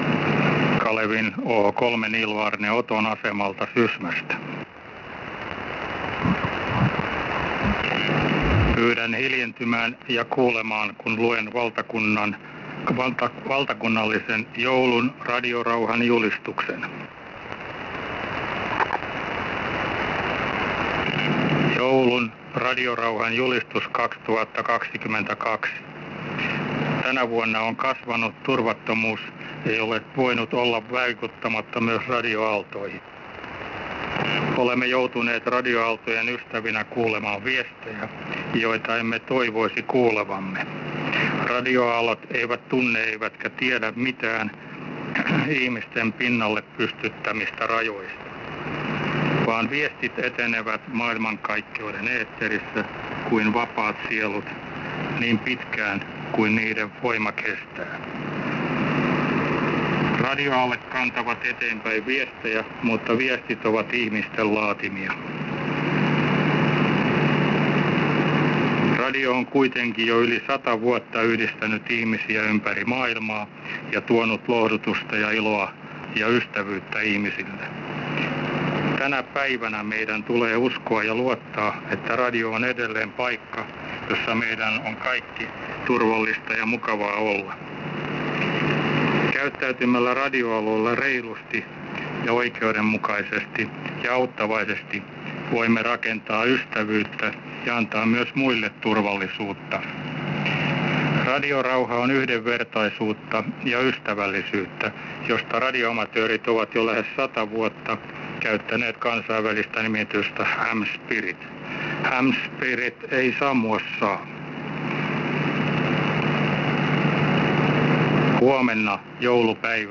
nauhoitus SDR-vastaanottimen kautta Tampereelta:
"Radiorauhan_julistus_ kuultuna Tampereella"